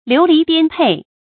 流離顛沛 注音： ㄌㄧㄨˊ ㄌㄧˊ ㄉㄧㄢ ㄆㄟˋ 讀音讀法： 意思解釋： 由于災荒或戰亂而流轉離散。